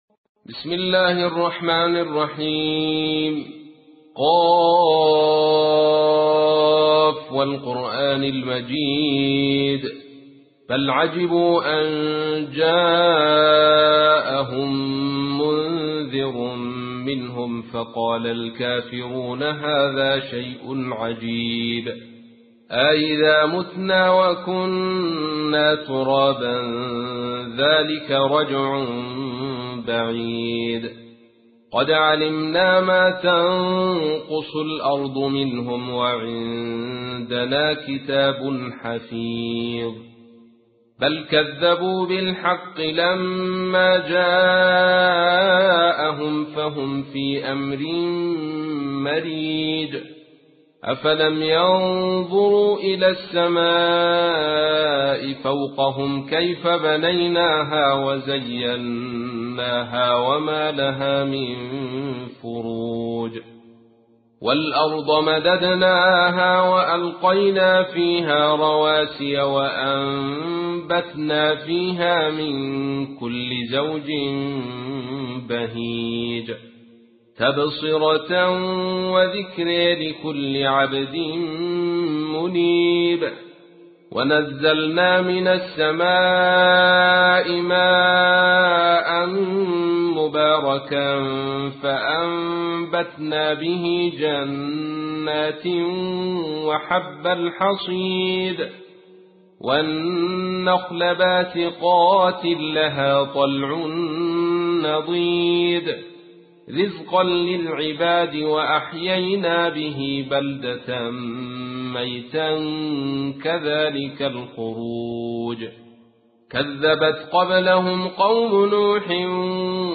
سورة ق / القارئ عبد الرشيد صوفي / القرآن الكريم / موقع يا حسين